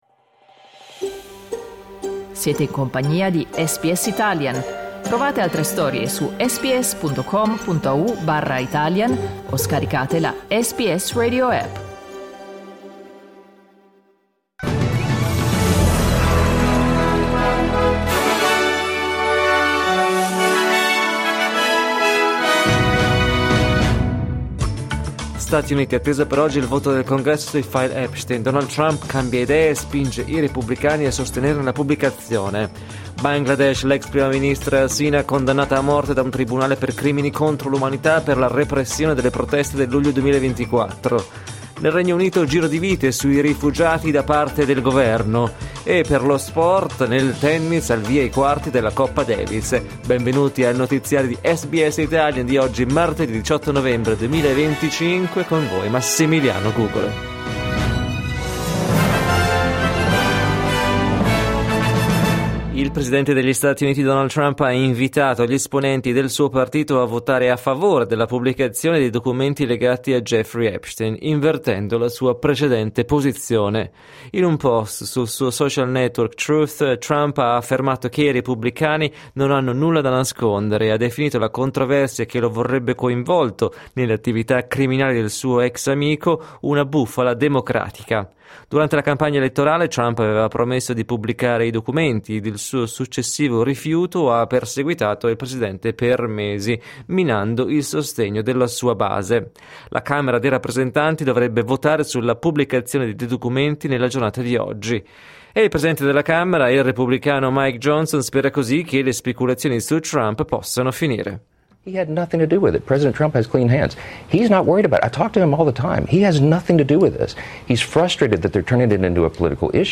Giornale radio martedì 18 novembre 2025
Il notiziario di SBS in italiano.